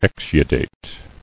[ex·u·date]